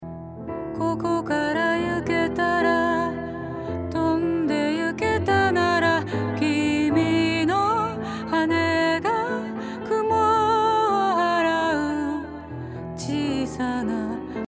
ディレイ
次にテンポに合わせていないもの。